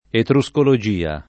etruscologia [ etru S kolo J& a ] s. f.